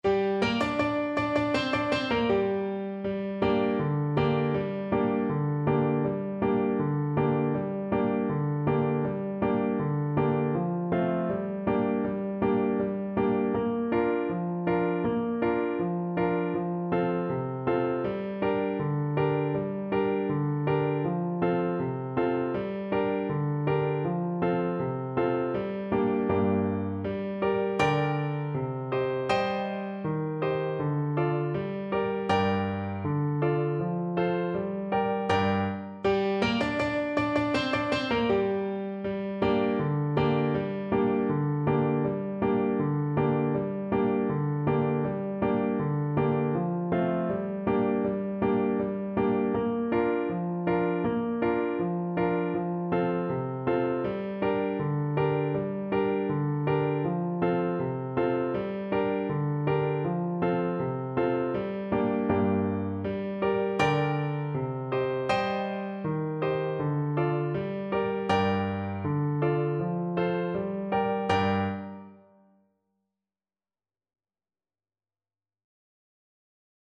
French Horn
G minor (Sounding Pitch) D minor (French Horn in F) (View more G minor Music for French Horn )
Pesante =c.80
2/4 (View more 2/4 Music)
Traditional (View more Traditional French Horn Music)
Chinese